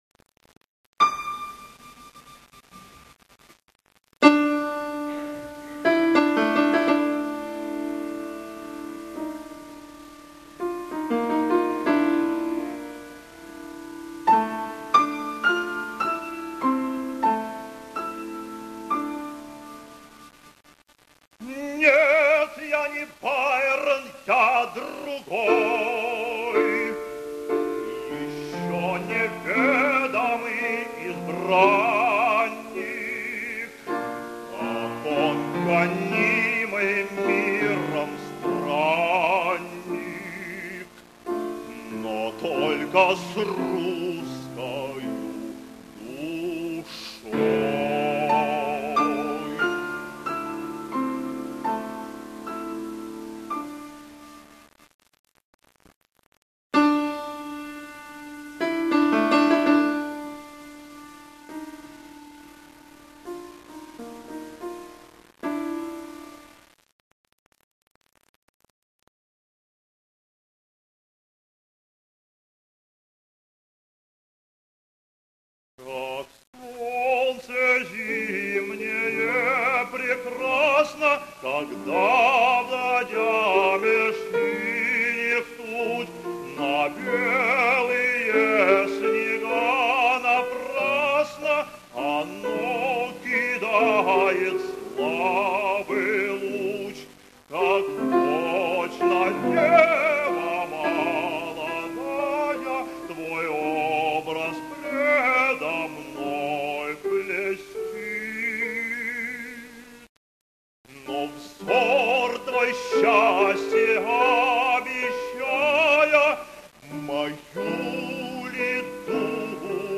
фортепиано